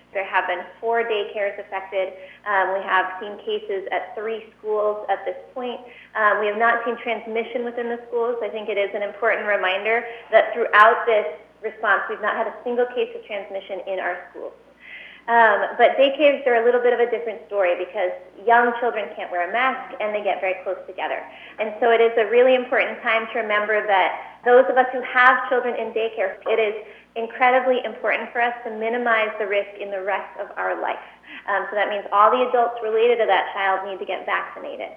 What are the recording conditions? PORT ANGELES – Friday morning’s Covid briefing focused largely on the pandemic’s recent effect on local kids, and we got some new information about when they may be able to start vaccinating children under 16.